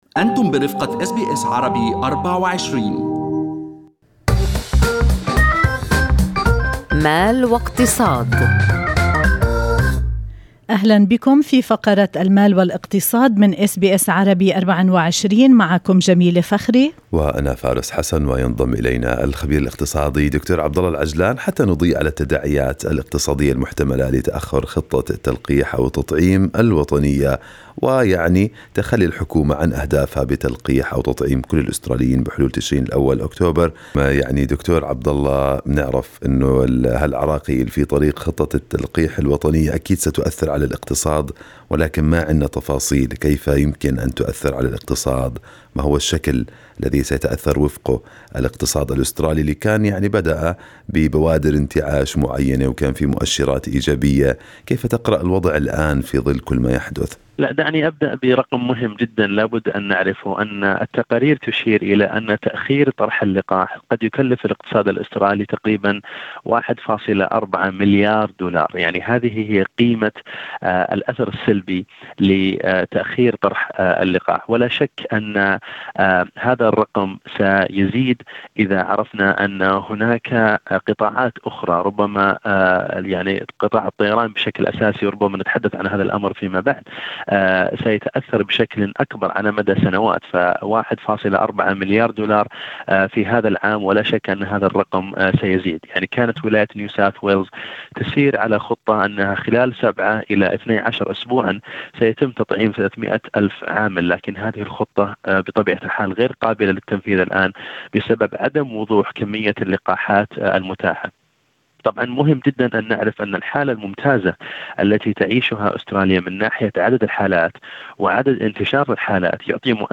وفي حديث له مع اس بي اس عربي 24